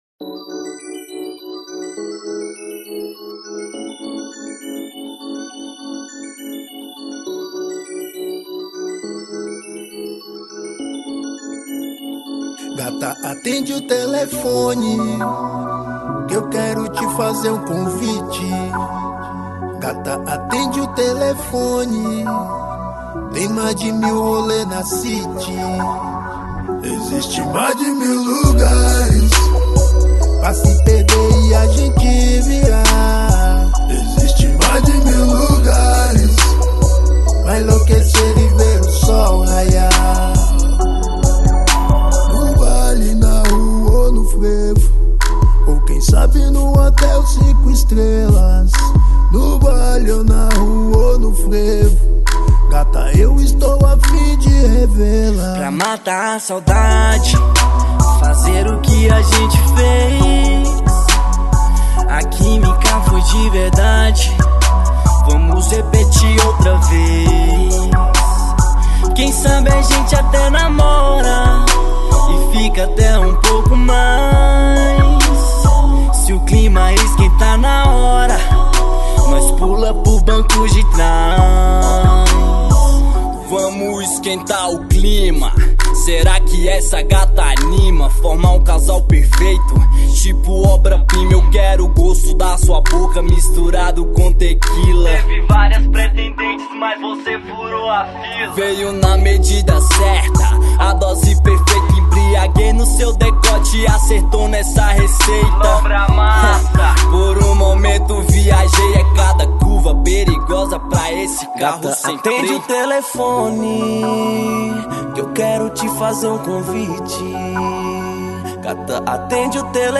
2025-03-17 19:22:31 Gênero: Rap Views